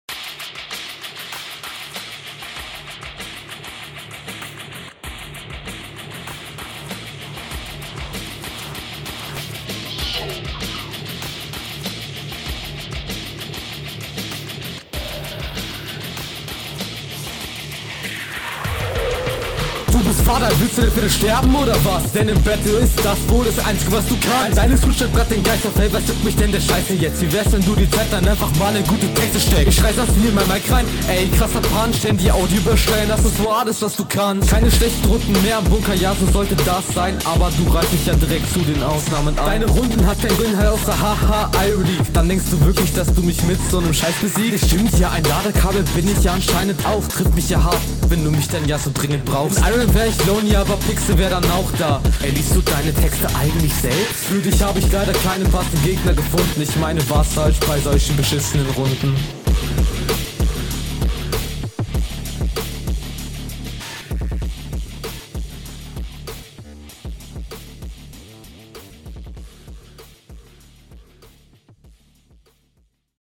Flow: Besser als der Gegner, mehr Variationen könnten nicht schaden Text: Ganz nice gekontert Soundqualität: …
Flow: Auch hier einige Flowfehler und ab und zu hatte ich auch mal Verständnisprobleme, aber …